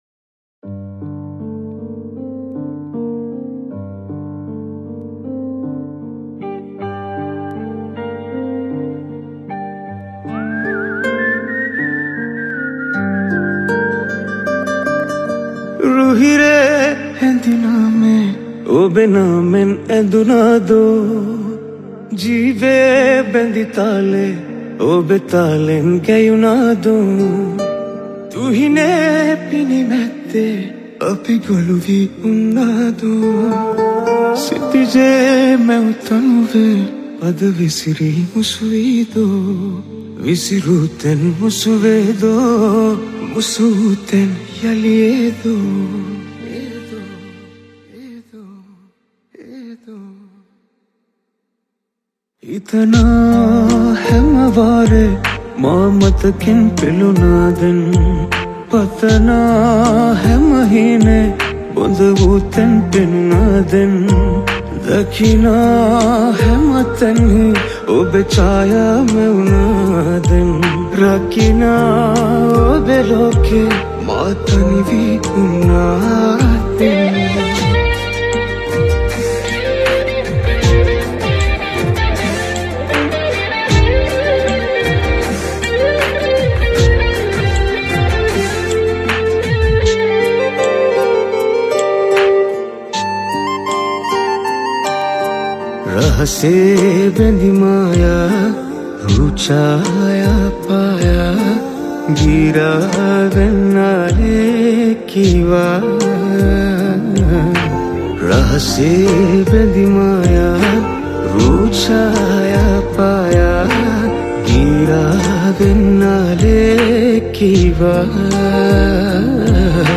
All keys
All guitars